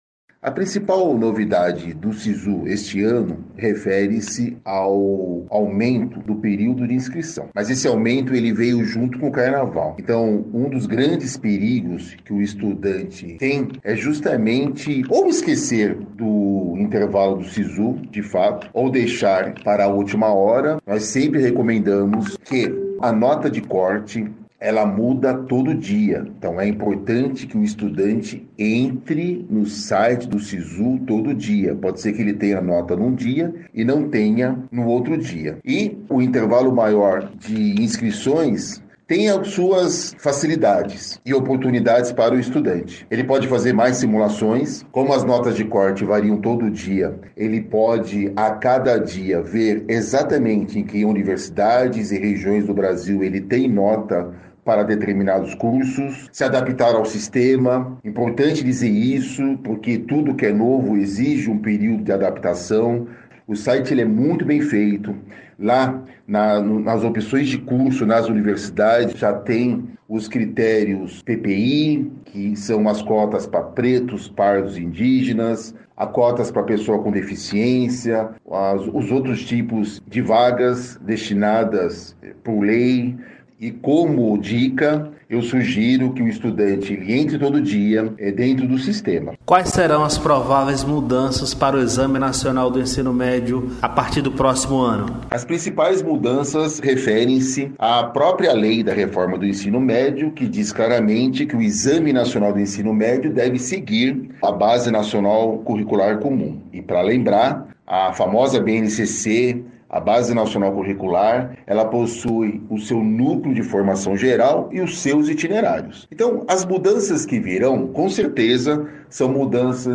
Diretor de cursinho pré vestibular